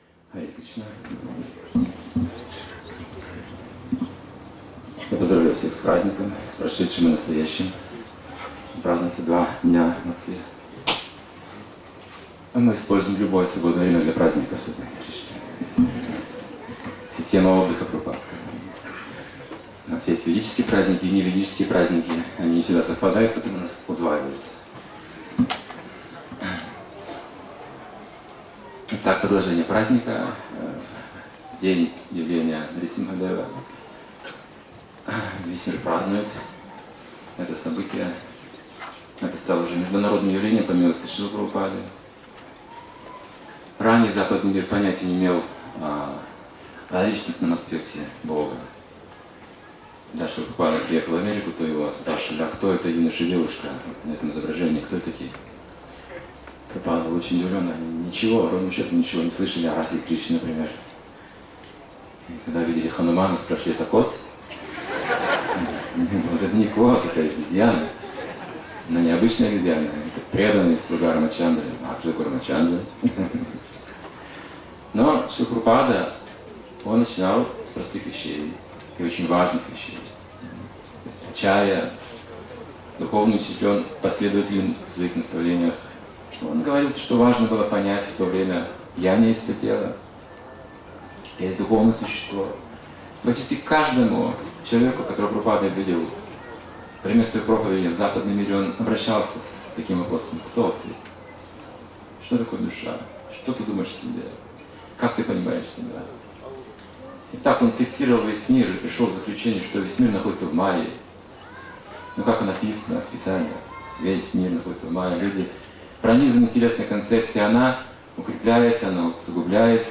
Лекция , посвященная Дню Явления Господа Нрисимхадева, раскрывает не только личностное понятие Господа, но и связь с ним - йогу, которая дарует счастье.